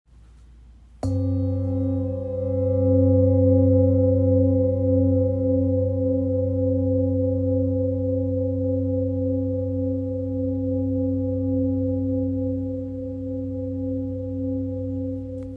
This enhanced oscillation makes it perfect for bodywork, as its deep, resonant tones penetrate the body, promoting relaxation, balancing energy centers, and restoring harmony on a cellular level.
Its rich tones and sustained vibrations make it a versatile tool for meditation, energy healing, and therapeutic use.